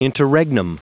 Prononciation du mot interregnum en anglais (fichier audio)
Prononciation du mot : interregnum